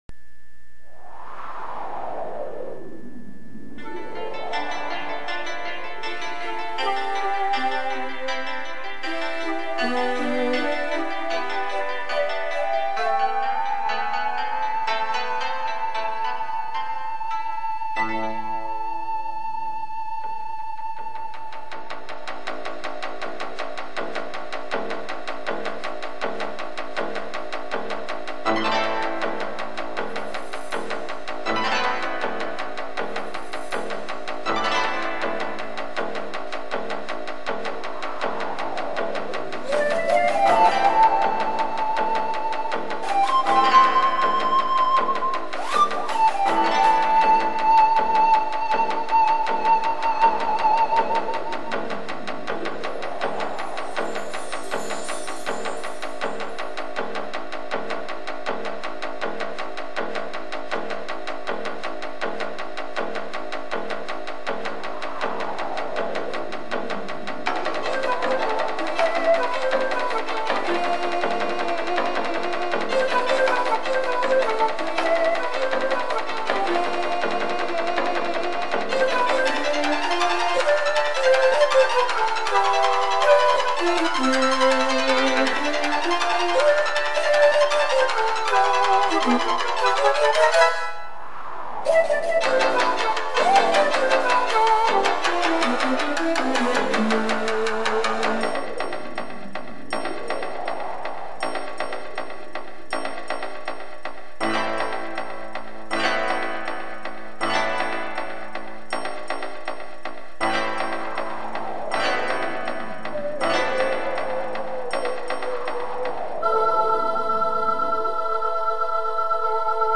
CM-300という昔の音源を使っています。
全ての曲はヘッドホンで聞くように設計されてます。
これもうるさめかな。でも実は瞑想用のアレンジなのです。